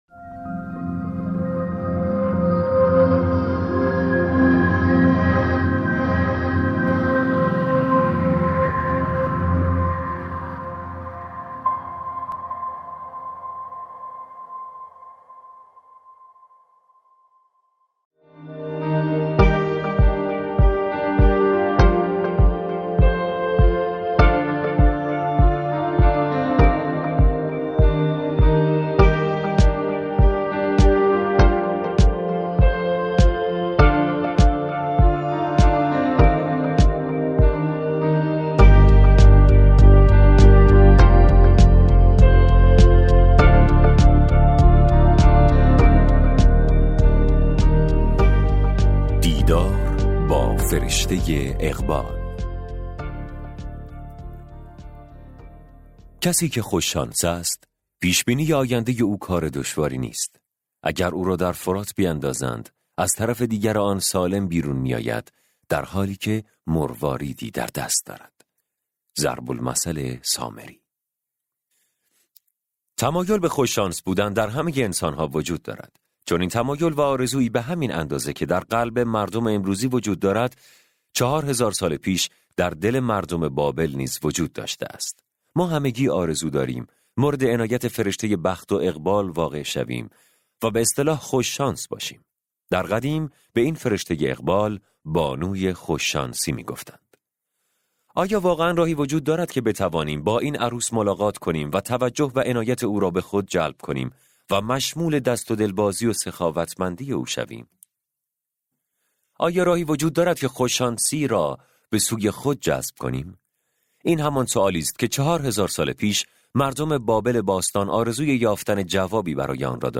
کتاب صوتی ثروتمندترین مرد بابل اثر جورج کلاوسون